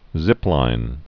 (zĭplīn)